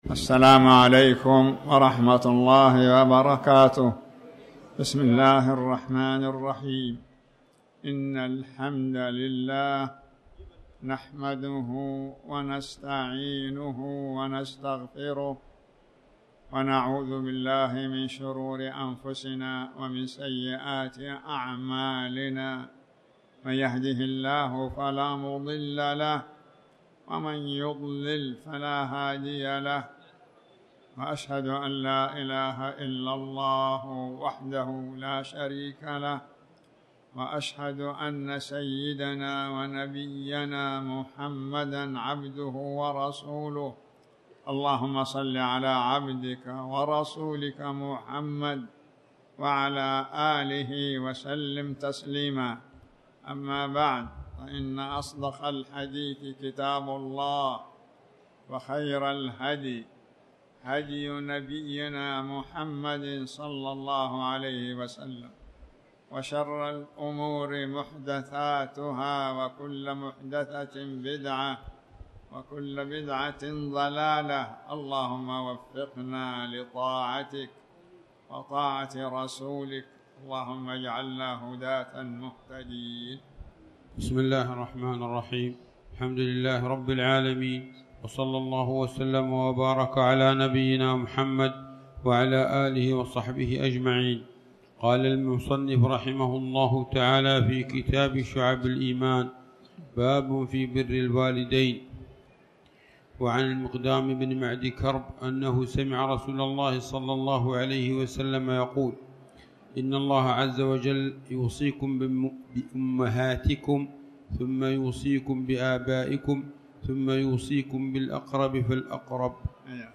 تاريخ النشر ١٩ شوال ١٤٣٩ هـ المكان: المسجد الحرام الشيخ